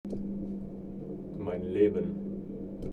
MS Wissenschaft @ Diverse Häfen